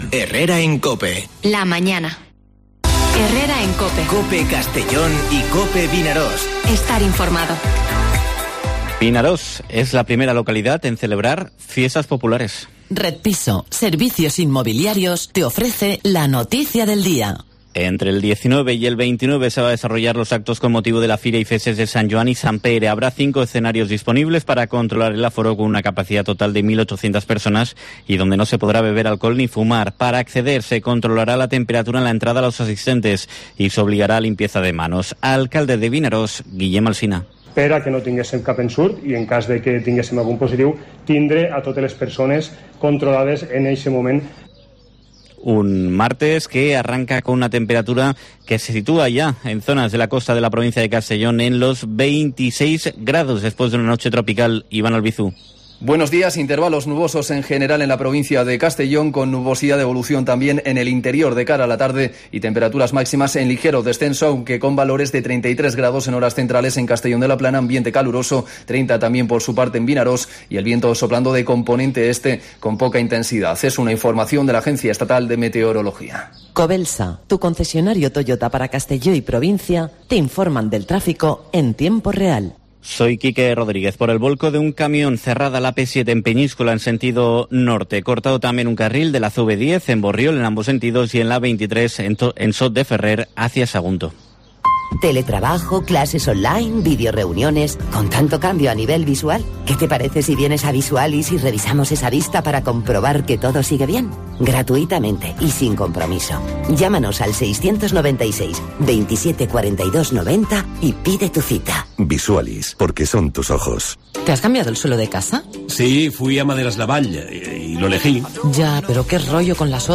Informativo Herrera en COPE en la provincia de Castellón (15/06/2021)
Noticias